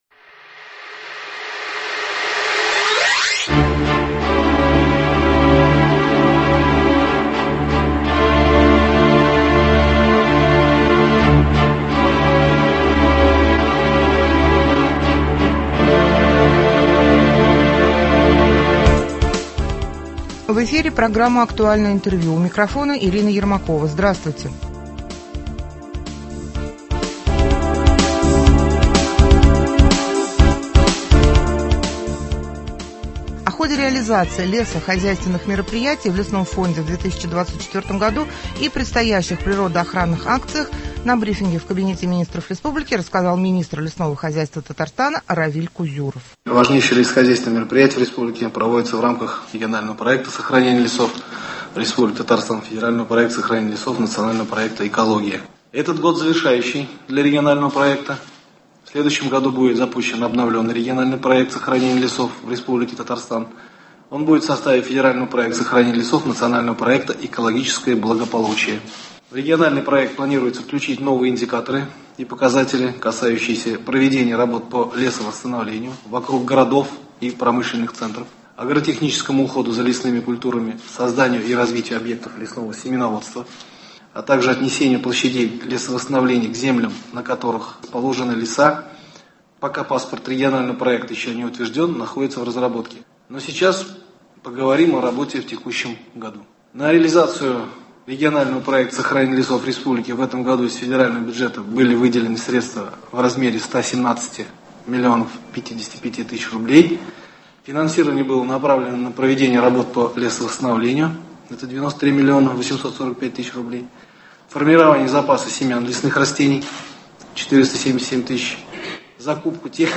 Актуальное интервью (11.09.24) | Вести Татарстан
В Доме Правительства РТ прошел брифинг по вопросам реализации лесохозяйственных и противопожарных мероприятий в лесном фонде в 2024 году и предстоящих природоохранных акциях.